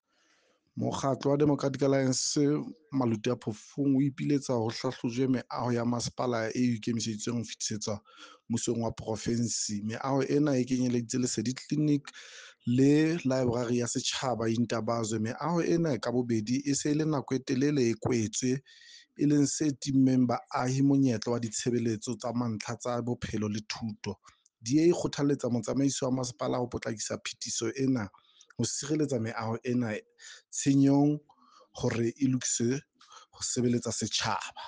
Sesotho soundbite by Cllr Paseka Mokoena.